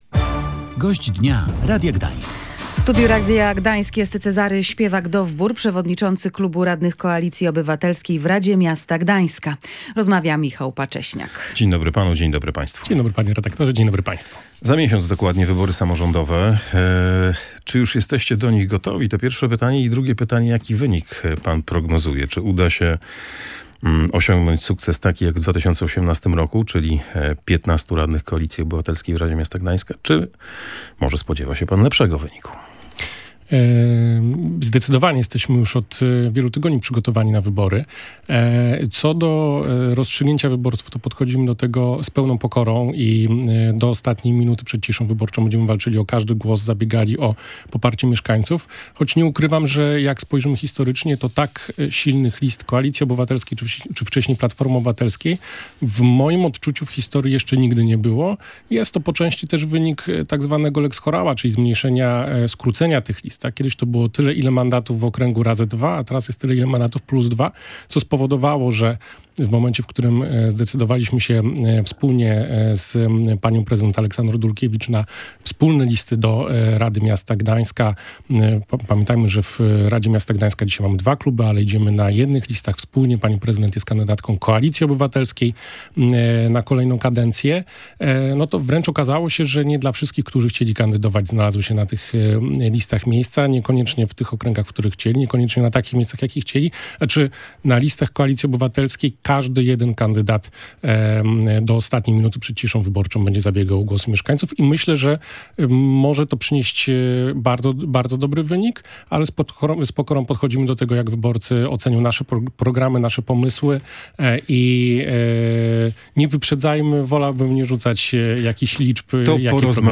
Jak prezentuje się przyszłość koalicji rządzącej i pomorskich inwestycji? W audycji „Gość Dnia Radia Gdańsk”
przewodniczącym klubu radnych Koalicji Obywatelskiej w Radzie Miasta